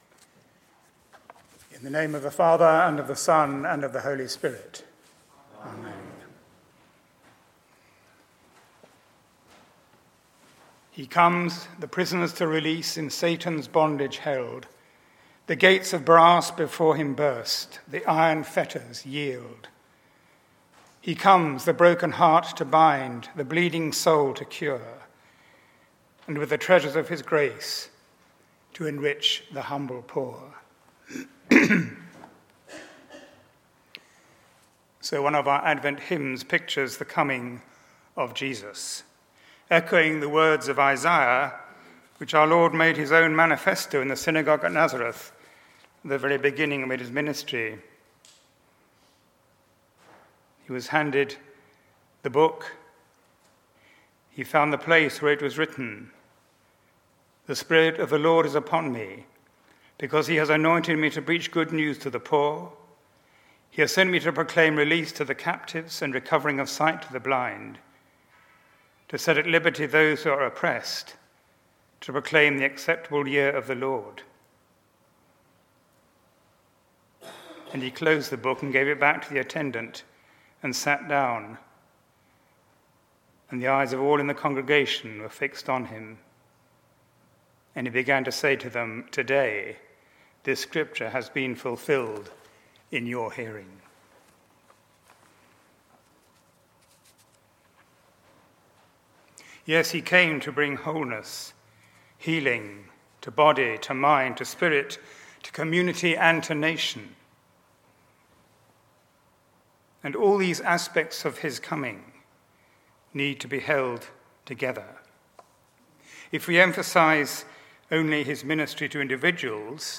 John 20:19-31 Service Type: Sunday